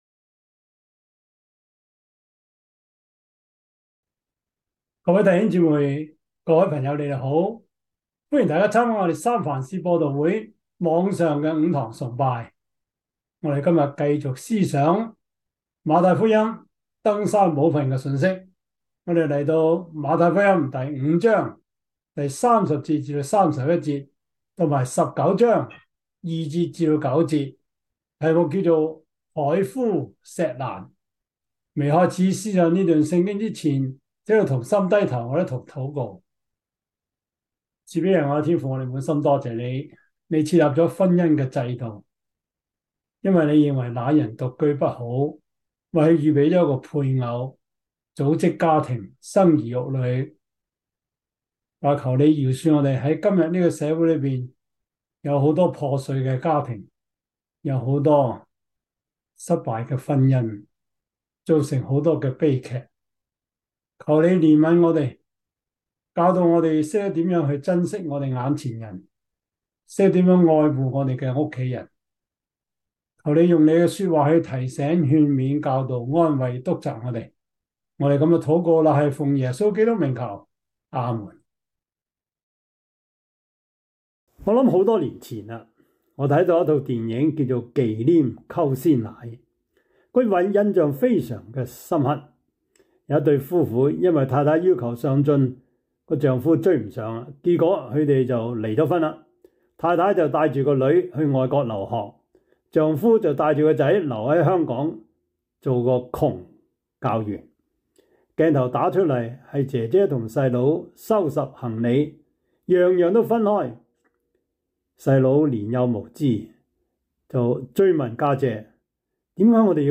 Service Type: 主日崇拜
Topics: 主日證道 « 團契生活 快樂人生小貼士 (二) – 第八課 »